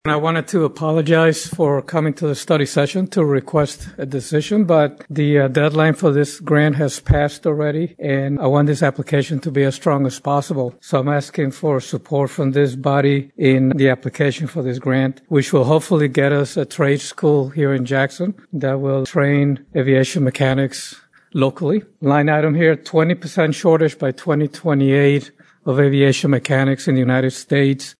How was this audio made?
Jackson, Mich. (WKHM) — The Jackson County Board of Commissioners unanimously approved a resolution in support of an Aviation Maintenance Technician School at the Jackson County Airport during their study session on Tuesday.